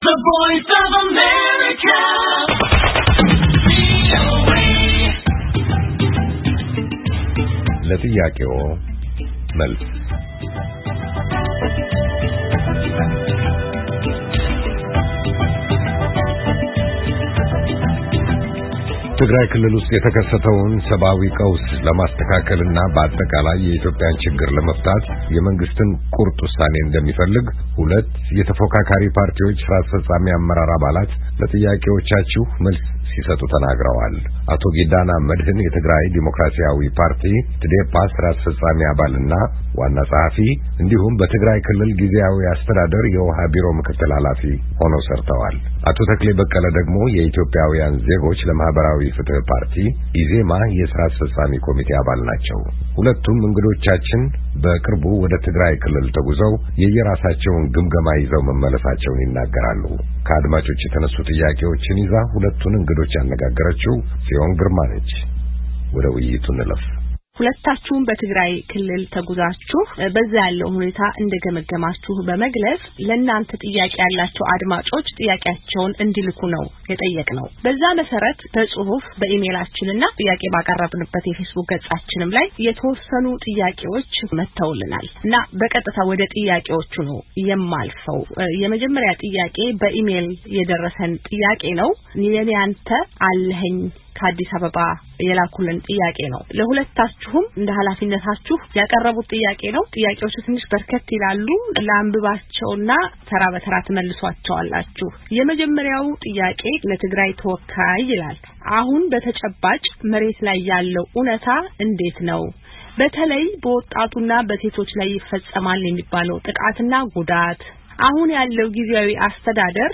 ሁለቱም እንግዶቻችን በቅርቡ ወደ ትግራይክልል ተጉዘው የየራሳቸውን ግምገማ ይዘው መመለሳቸውን ይናገራሉ። ከአድማጮች የተነሱ ጥያቄዎችን ይዘን ሁለቱን እንግዶች አነጋግረናል።